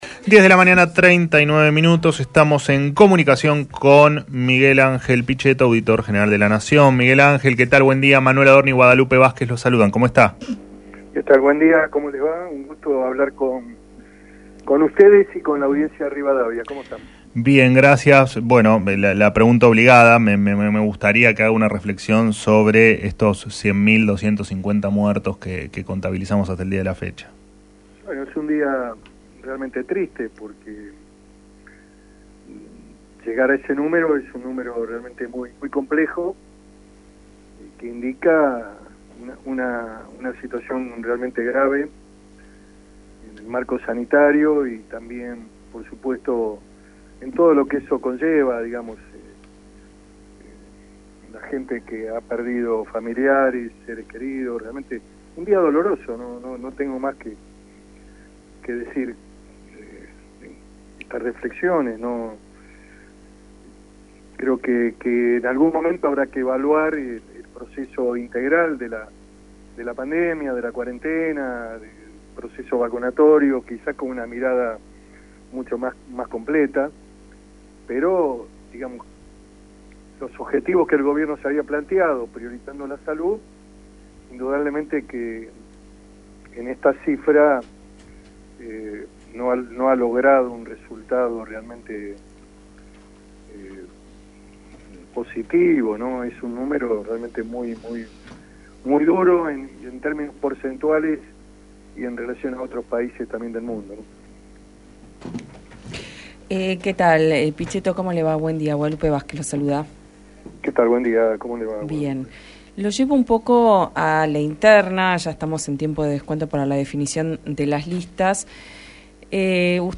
Miguel Ángel Pichetto, auditor general de la Nación, conversó en Alguien Tiene que Decirlo sobre su decisión de no presentarse en las próximas elecciones. Además, reflexionó acerca de los 100 mil muertos por covid que alcanzó la Argentina.